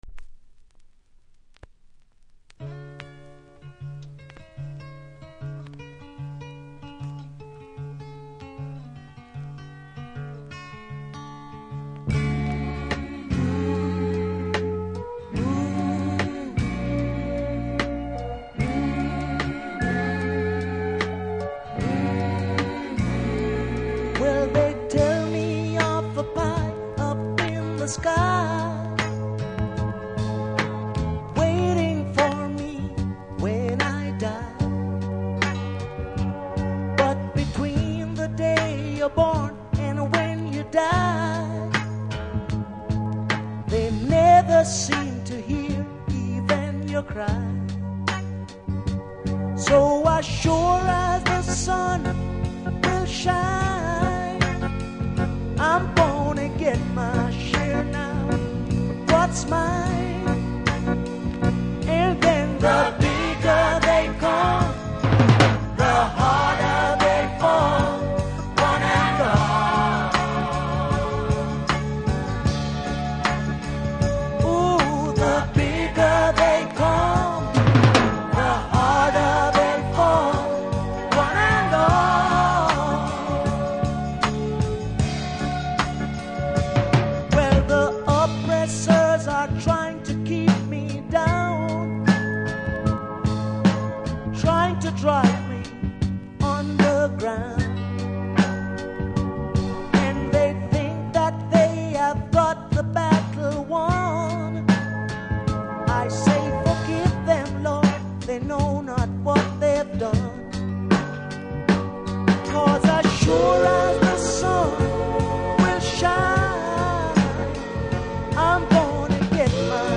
序盤キズにより少しノイズ感じますので試聴で確認下さい。